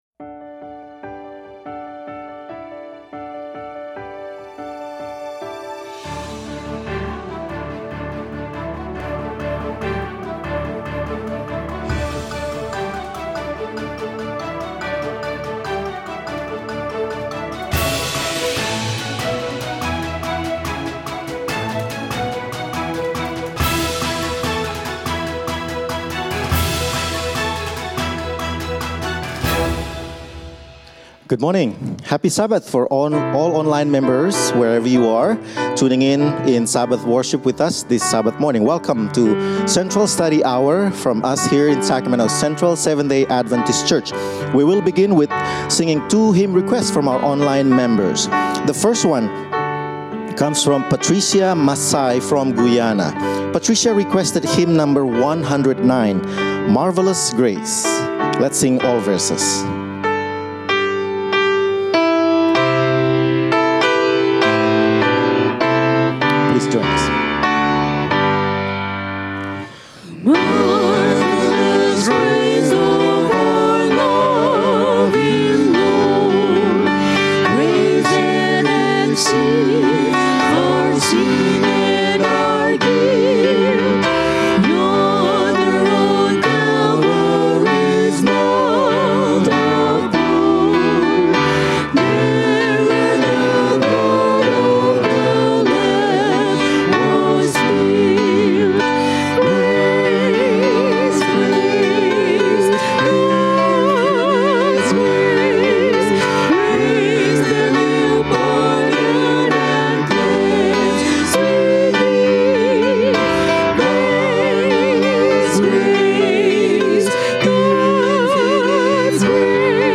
A message from the series "Making Friends For God."